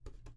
描述：在木手上的小敲